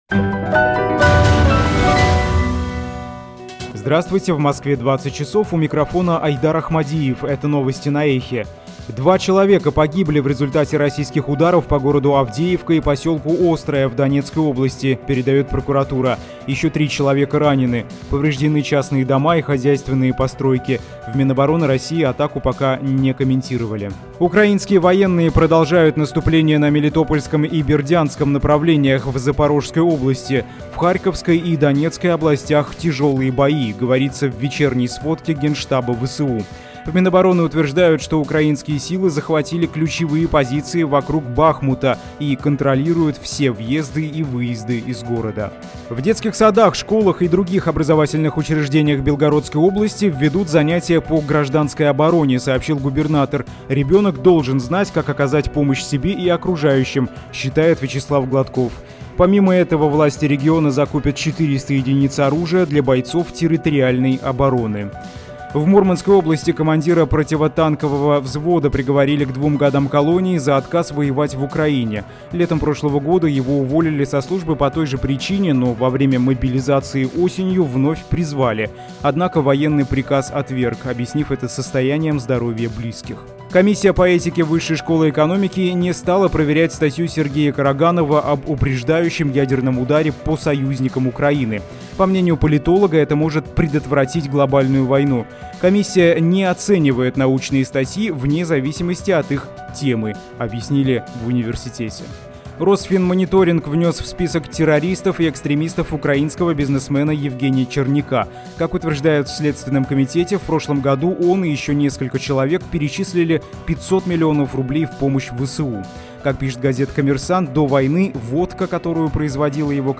Новости